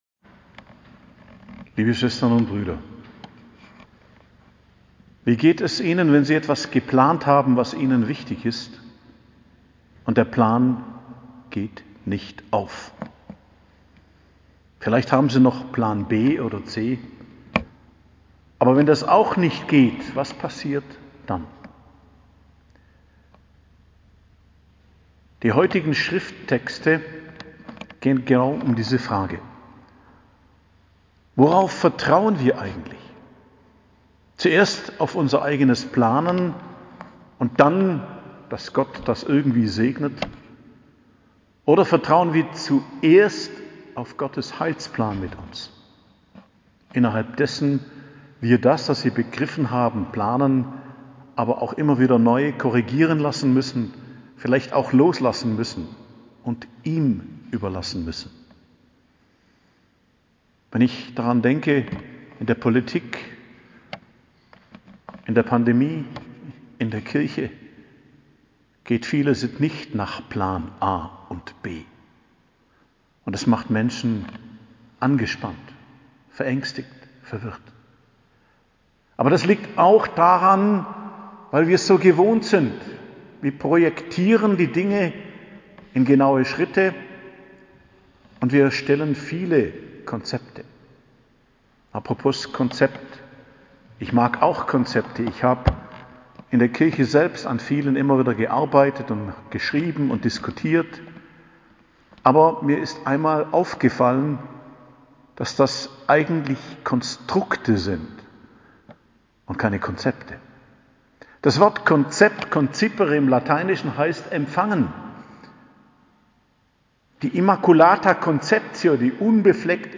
Predigt am Mittwoch der 7. Woche im Jahreskreis, 23.02.2022